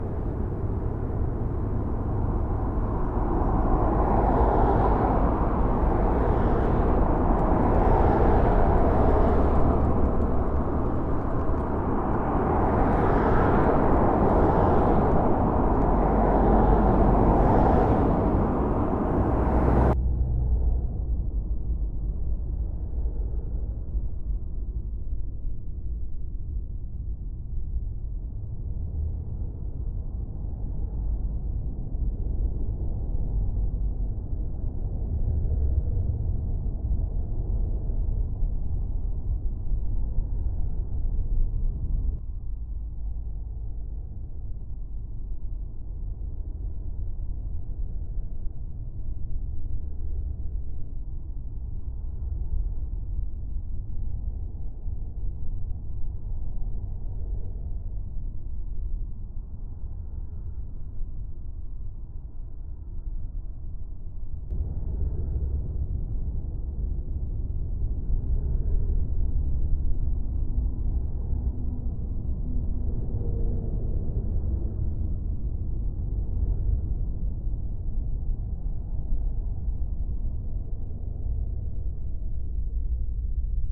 道路騒音に対しての防音効果
[ 高速道路脇で 防音箱を使った比較録音 ]
field-verification-of-soundproofing-performance-on-highways-03.mp3